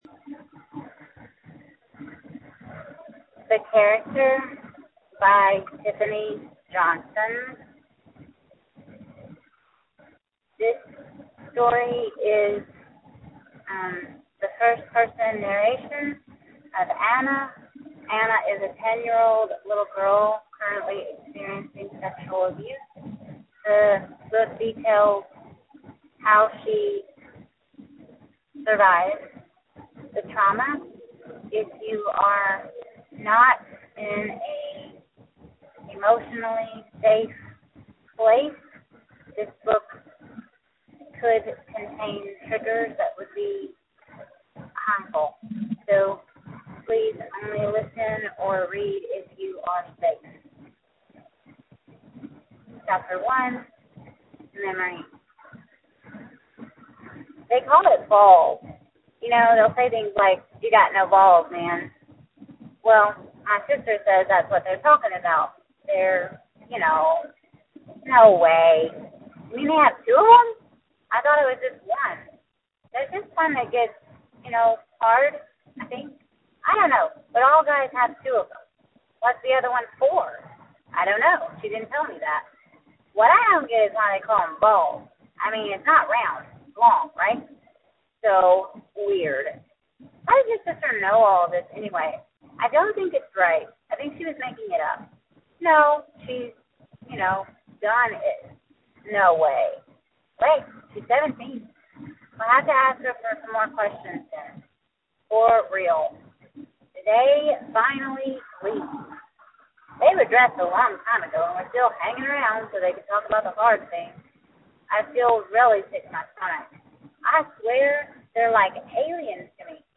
The Character: A Reading